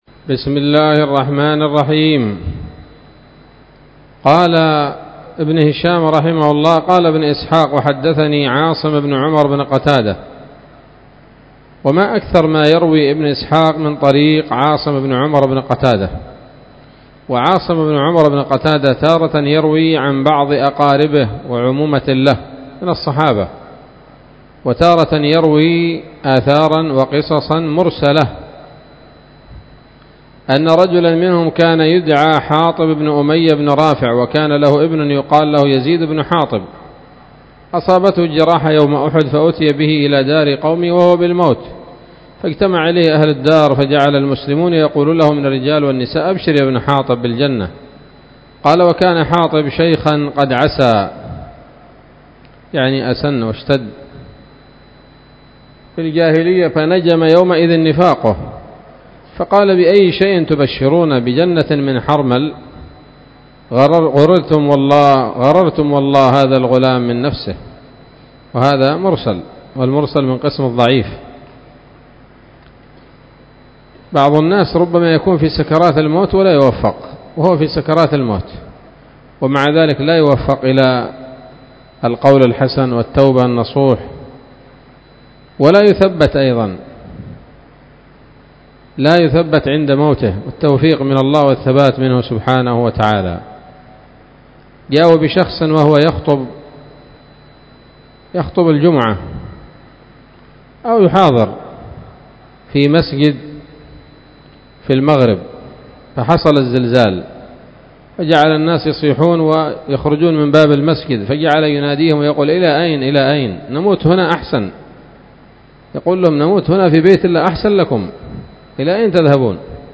الدرس الثاني والستون بعد المائة من التعليق على كتاب السيرة النبوية لابن هشام